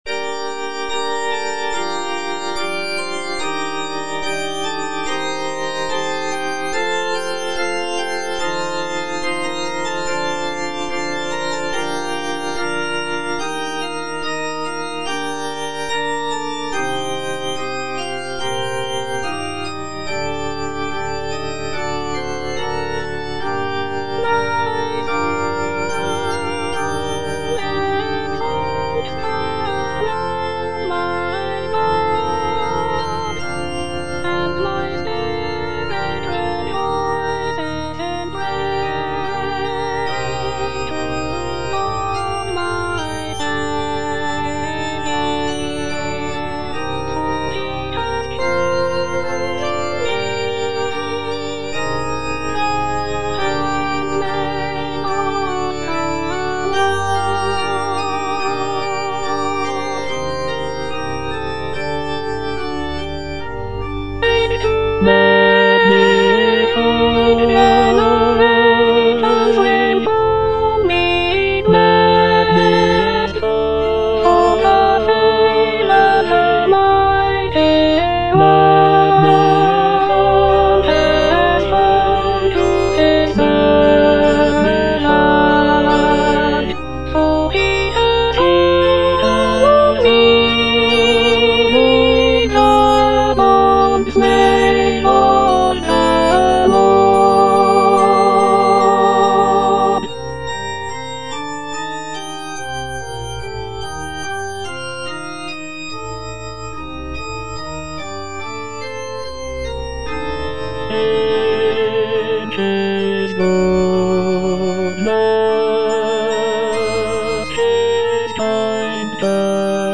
Alto (Emphasised voice and other voices)
choral piece